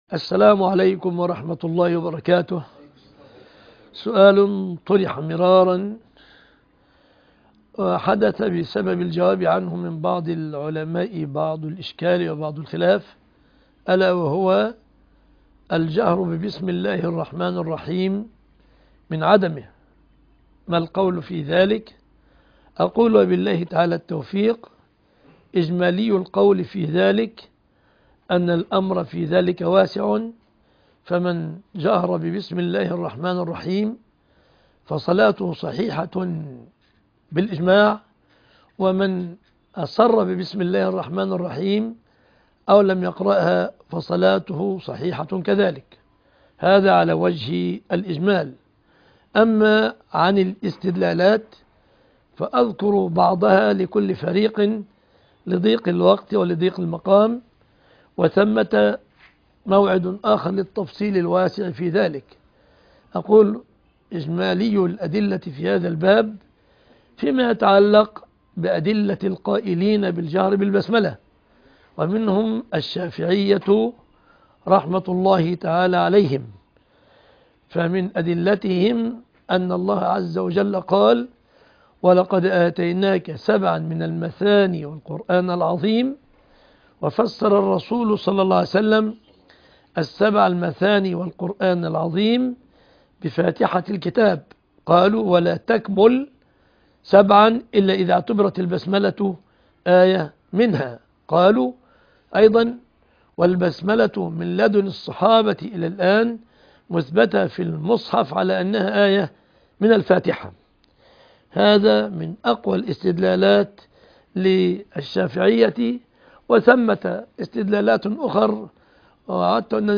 عنوان المادة فتوى في البسملة ـ الشيخ مصطفى العدوي تاريخ 9 3 2025